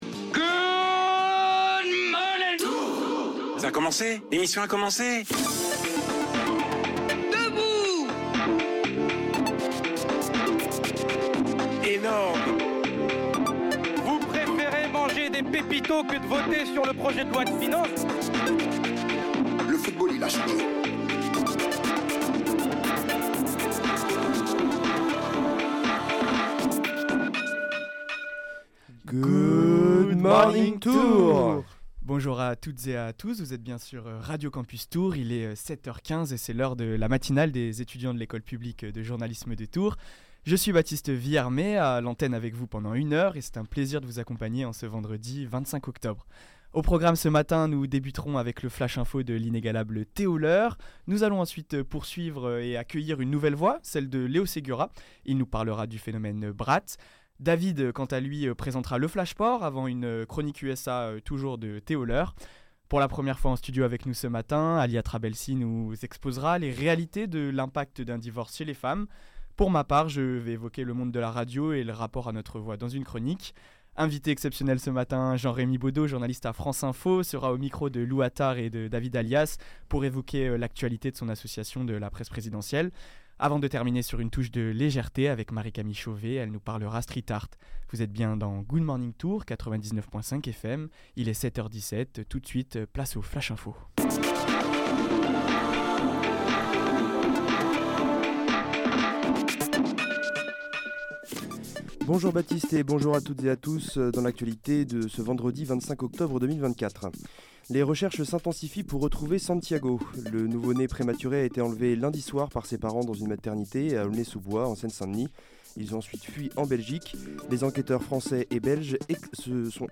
La matinale des étudiants de l’École publique de journalisme de Tours, le vendredi de 7 h 15 à 8 h 15.